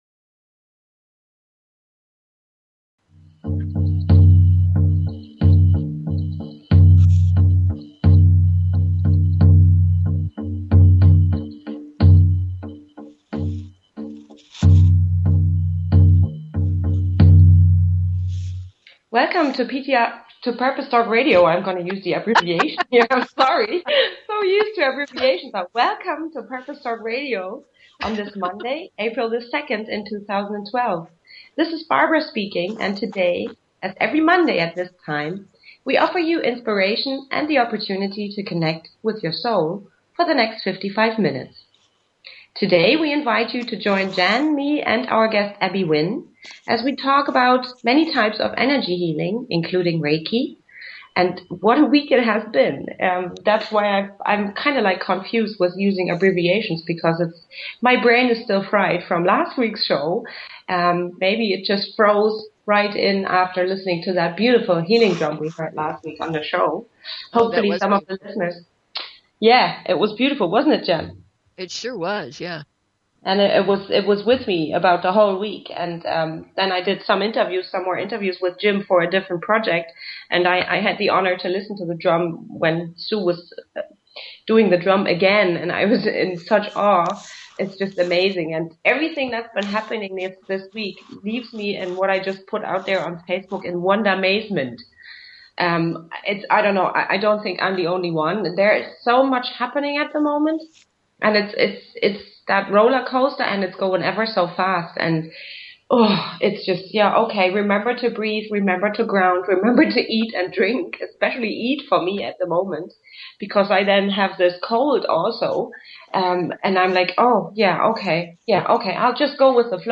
Talk Show Episode, Audio Podcast, Purpose_Talk_Radio and Courtesy of BBS Radio on , show guests , about , categorized as